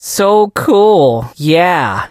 sandy_lead_vo_05.ogg